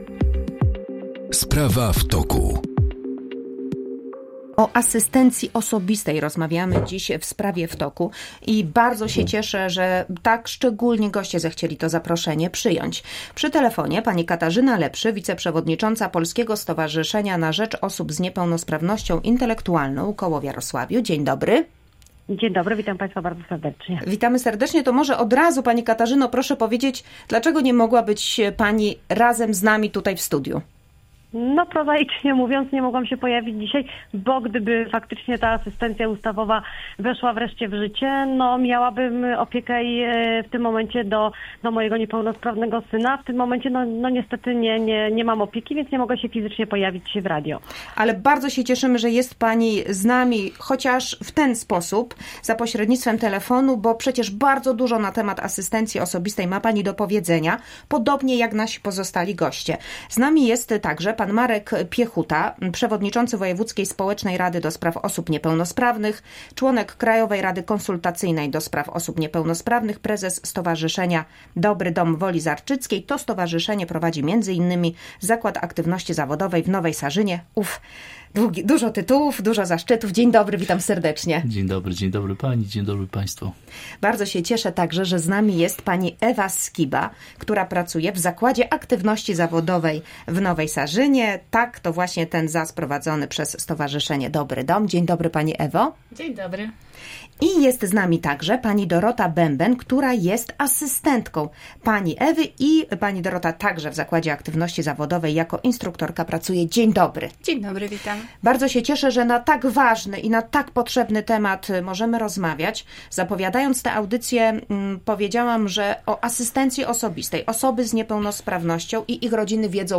Gośćmi studia byli przedstawiciele Stowarzyszenia DOBRY DOM